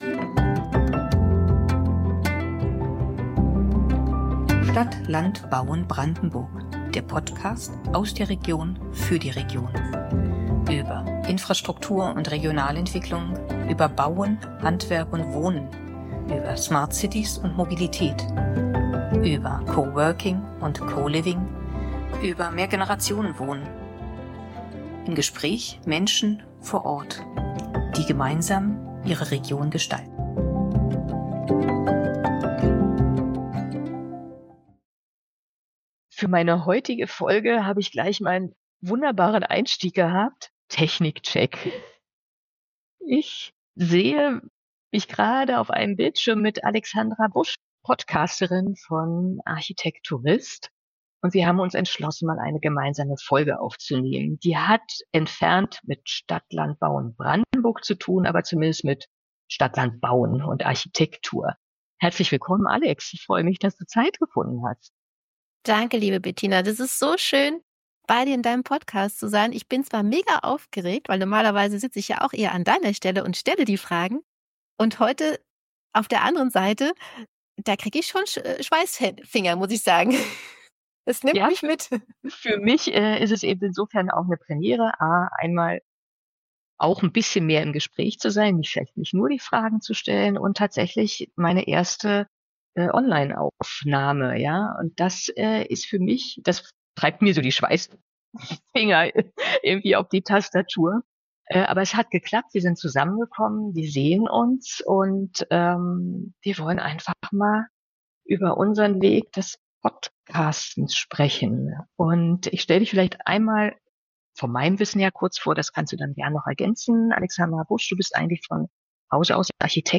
Gemeinsam tauschen wir uns über unsere Erfahrungen im Podcasten aus – von den ersten Ideen bis hin zu unseren persönlichen Ansprüchen an Inhalt und Stil. Wir sind uns einig: Podcasten ist eine Herzensangelegenheit, die Leidenschaft und Zeit erfordert und unglaublich den Horizont erweitert.